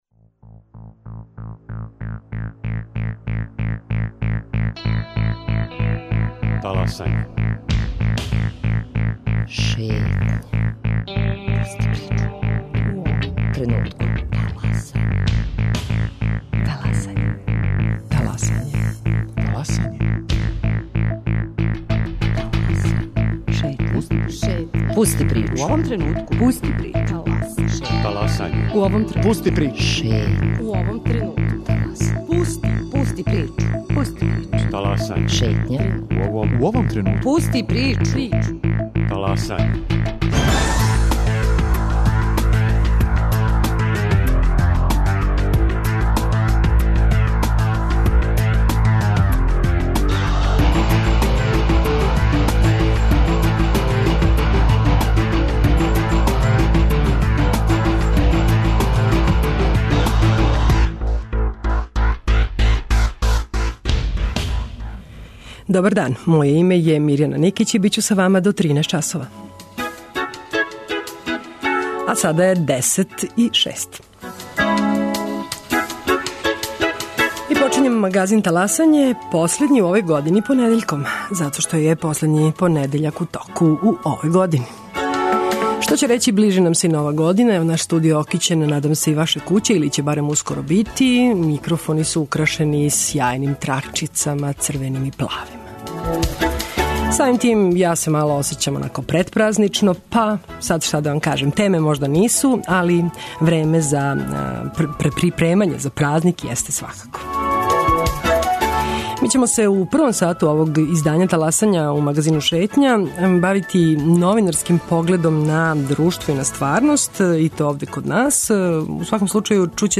У шетњи са три новинара. У оквиру размене новинара из Београда и Приштине, од маја до децембра, 15 новинара из два града боравила су по недељу дана у редакцијама београдских и приштинских медија.